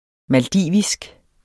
Udtale [ malˈdiˀvisg ]